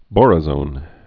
(bôrə-zŏn)